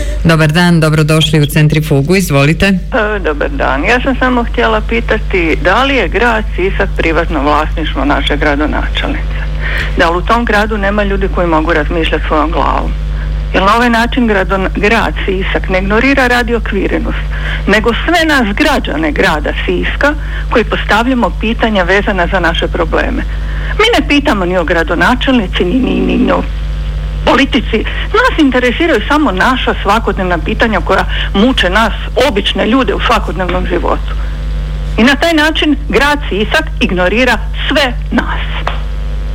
Je li Grad Sisak privatno vlasništvo gradonačelnice, pitala je slušateljica u emisiji Centrifuga, emitiranoj u našem programu u utorak, 8. studenog 2022. godine.